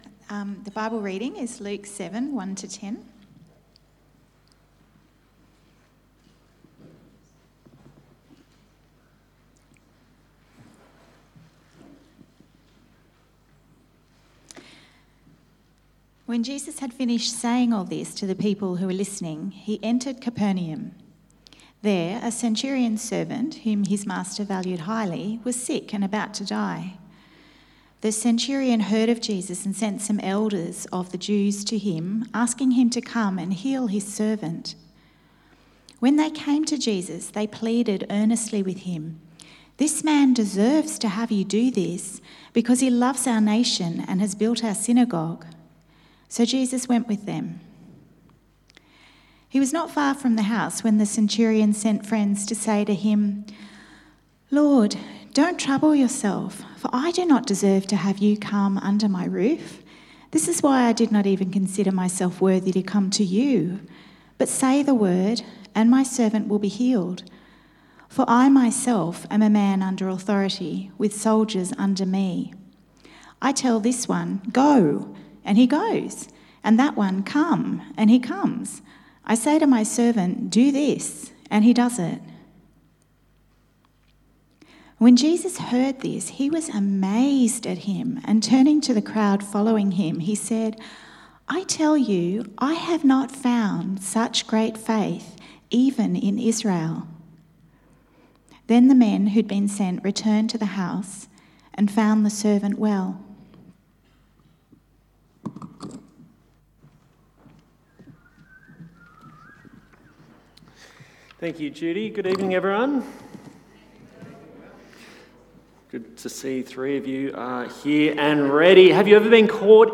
Preacher
Service Type: 6PM